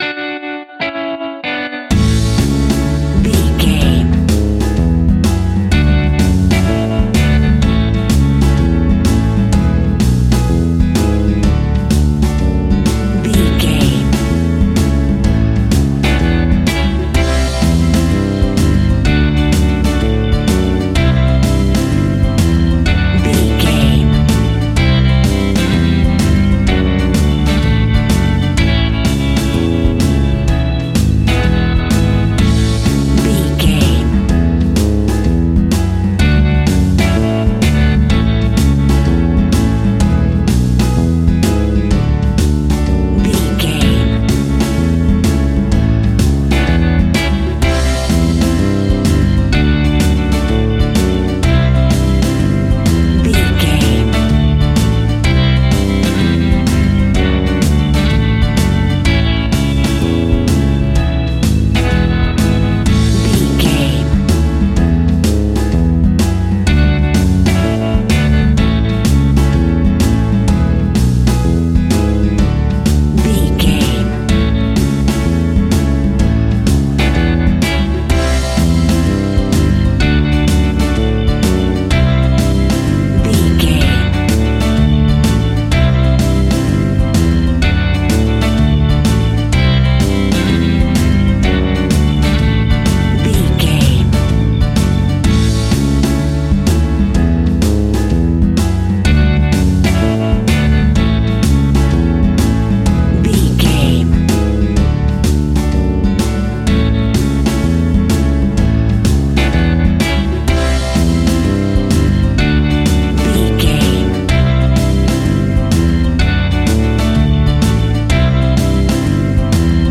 Country Bouncy Folk.
Ionian/Major
happy
upbeat
drums
bass guitar
electric guitar
hammond organ
acoustic guitar
percussion